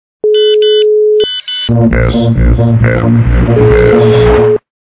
При прослушивании Звонок для СМС - Сигнал СМС и мужской голос качество понижено и присутствуют гудки.
Звук Звонок для СМС - Сигнал СМС и мужской голос